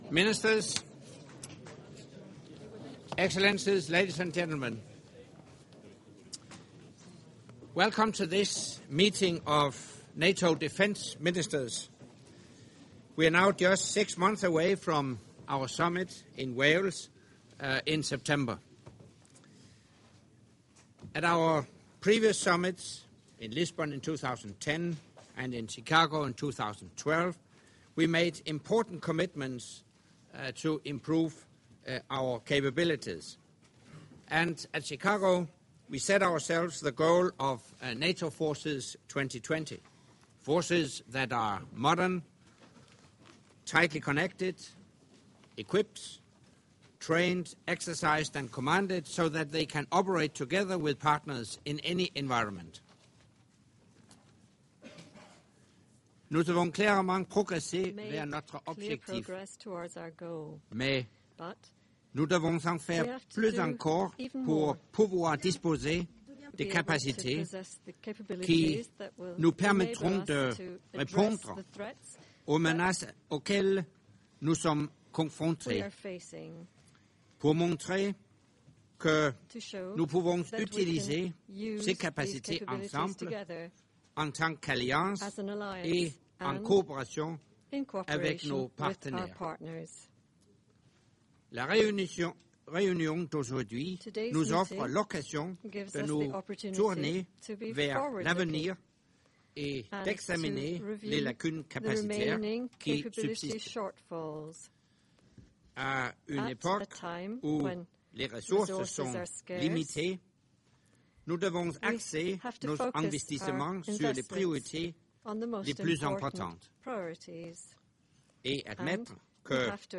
Opening remarks by NATO Secretary General Anders Fogh Rasmussen
at the meeting of the North Atlantic Council in Defence Ministers session